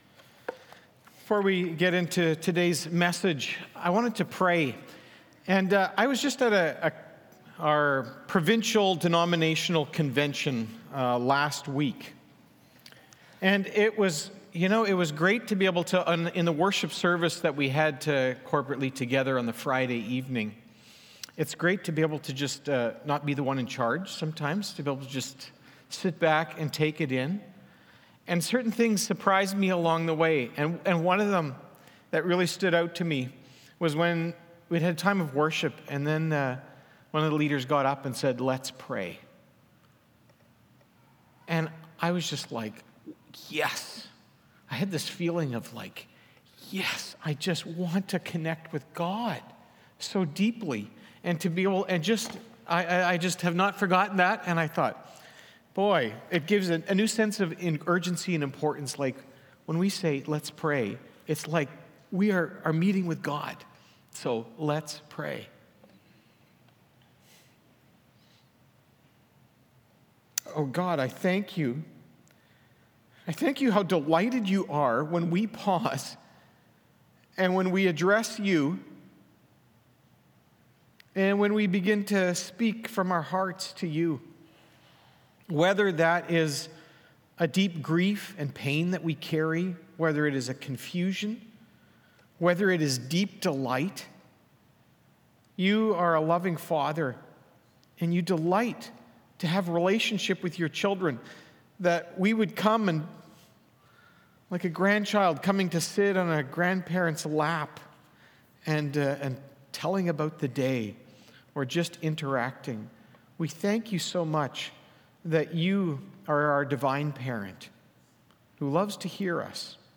Sermons | Eagle Ridge Bible Fellowship